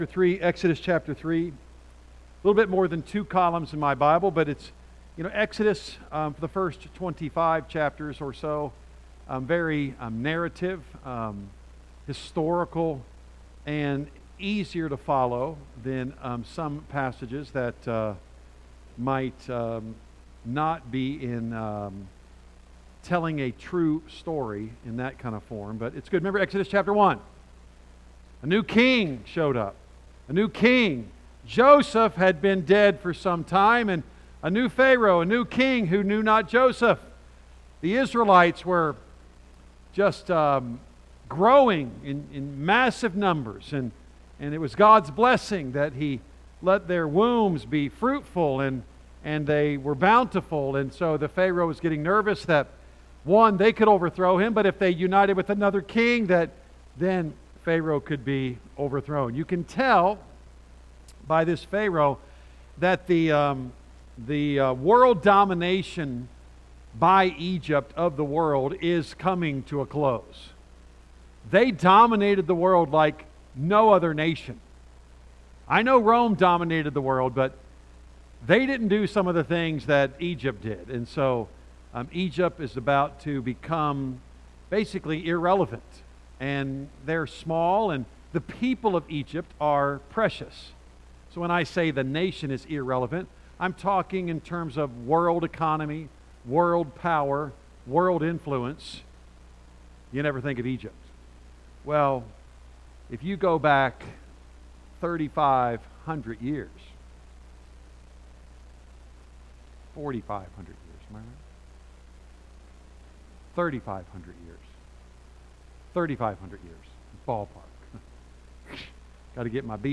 A message from the series "Exodus."